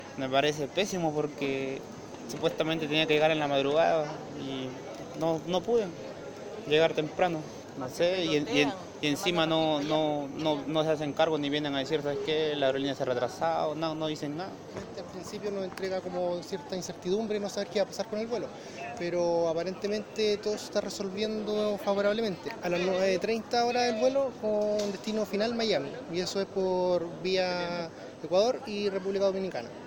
Los pasajeros de esos vuelos comentaron a Radio Bío Bío los inconvenientes que tuvieron que aguantar ante el retraso de las salidas.